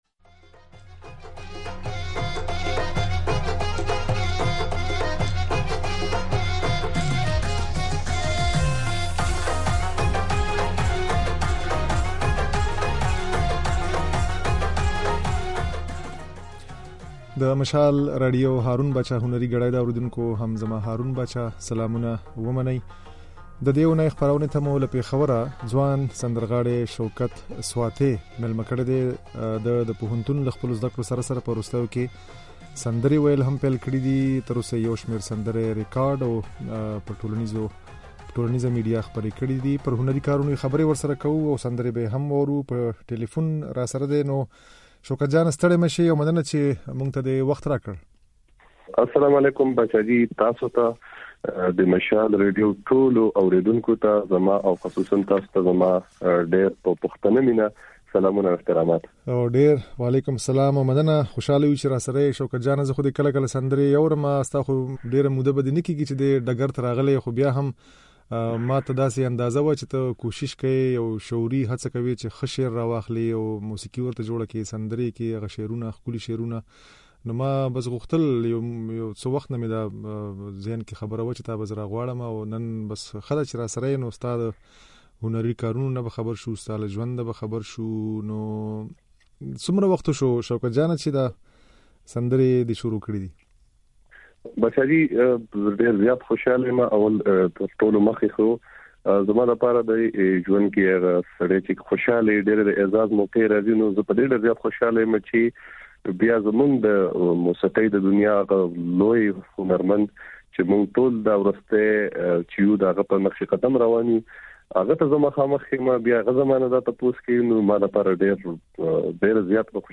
دا خبرې او ځينې سندرې يې د غږ په ځای کې اورېدای شئ.